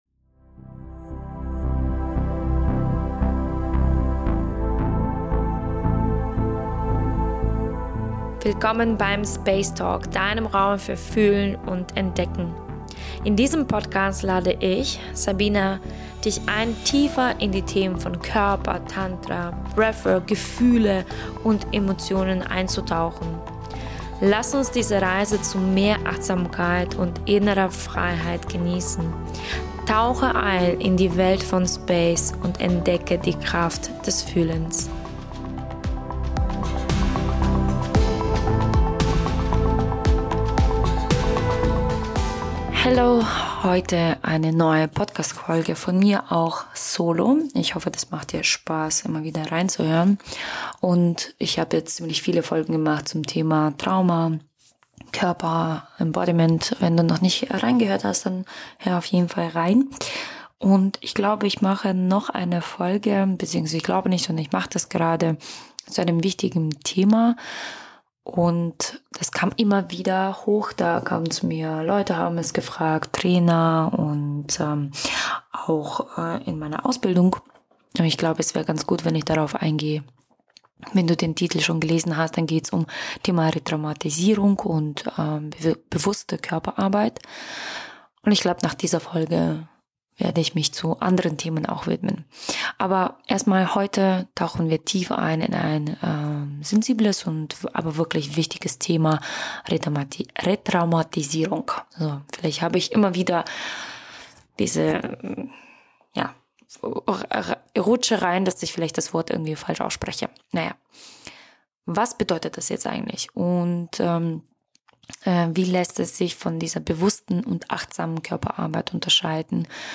In dieser tiefgehenden Solo-Folge